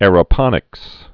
(ârə-pŏnĭks)